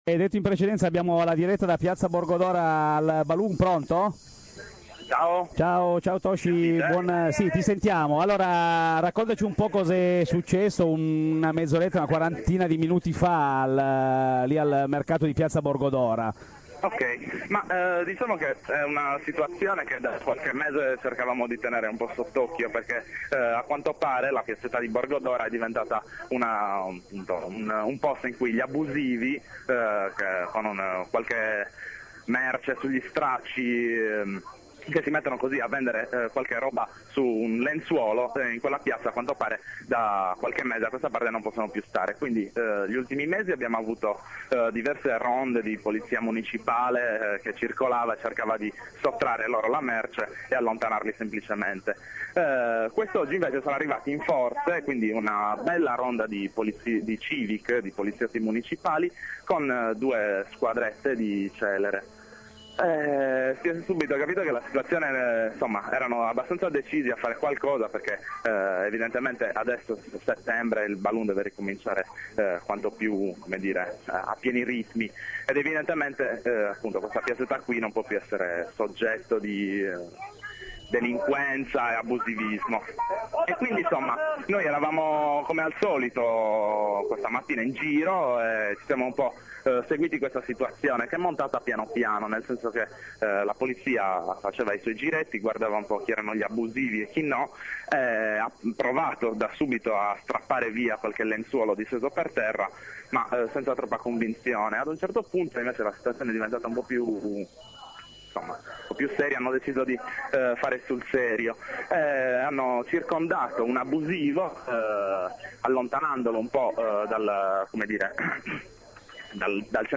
cariche-a-borgo-dora.mp3